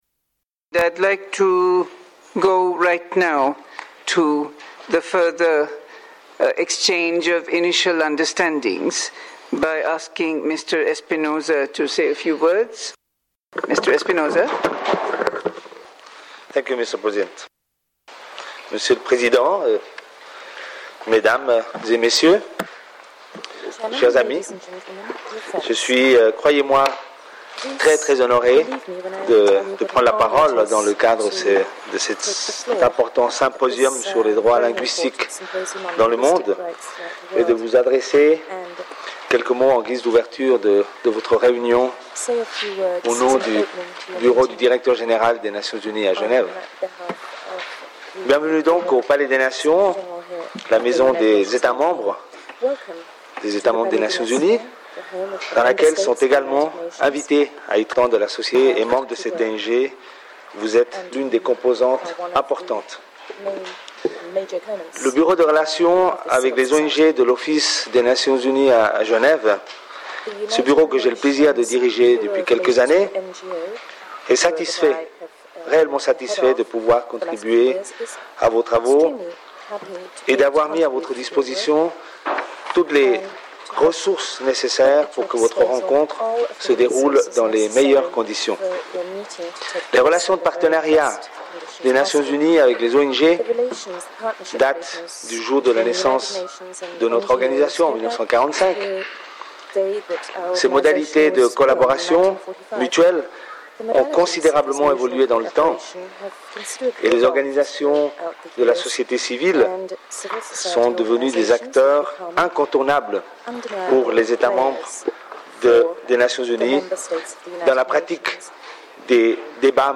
Genève, Palais des Nations, Salle VIII, 24 avril 2008